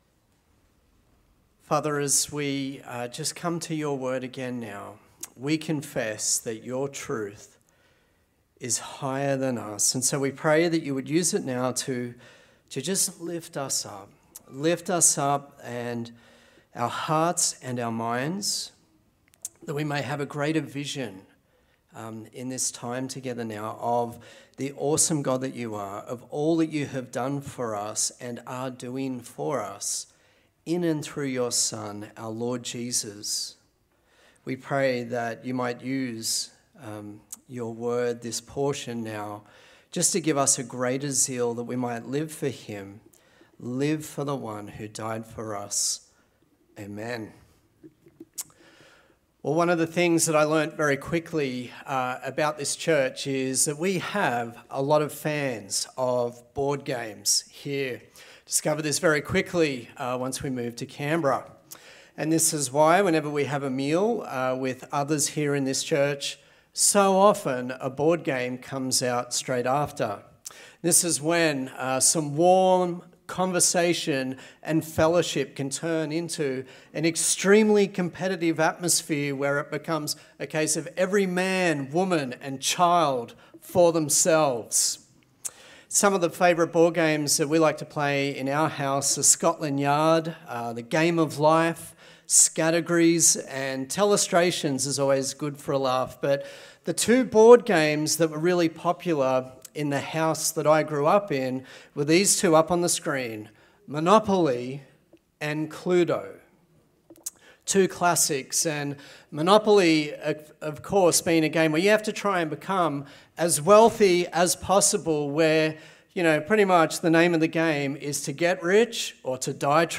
A sermon in the series on the book of James
Service Type: Sunday Service